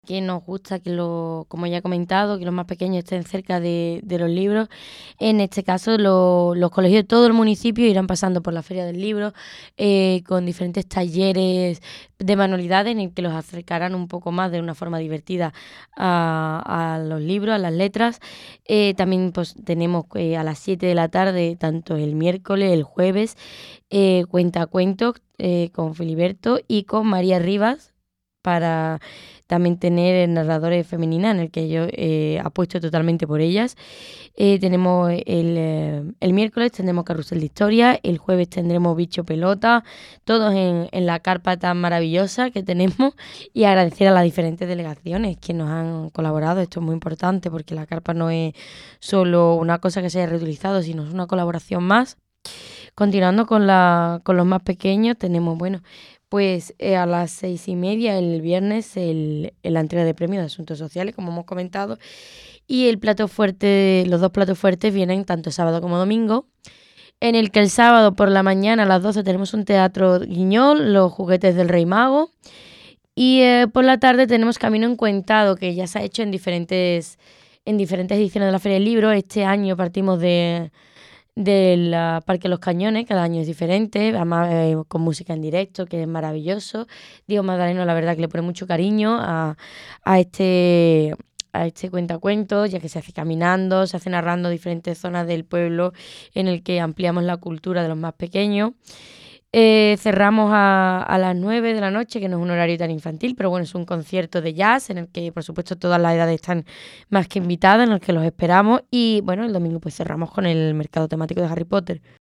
Rocío Fernández destaca en la radio la programación infantil de la VI Feria del Libro
El certamen se prolongará hasta el domingo 30 de marzo y contará con firmas de autores, talleres, representaciones teatrales, cuentacuentos y un Mercado Temático Harry Potter con el que finalizará esta nueva edición. La concejal de Bibliotecas, Rocío Fernández, presentó la programación en Canal San Roque Radio e incidió en las actividades infantiles.
TOTAL ROCIO FDEZ RADIO.mp3